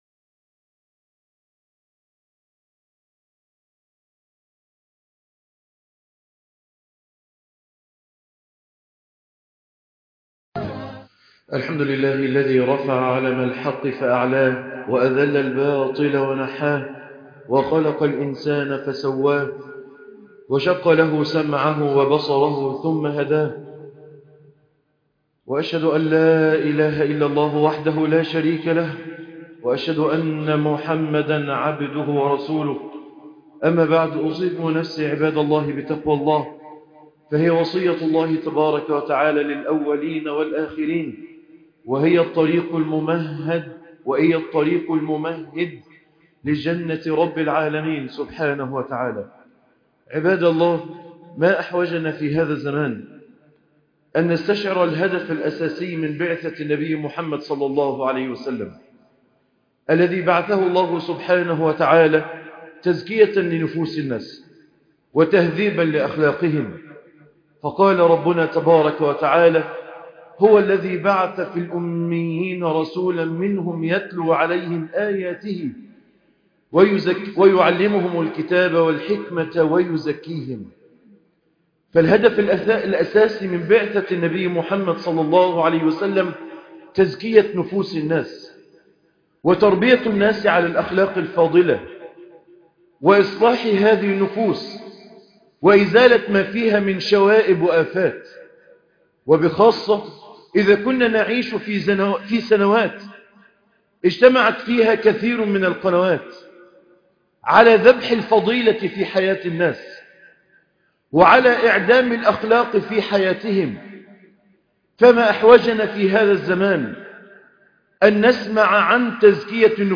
الحياء من الله سبحانه وتعالى - خطب الجمعة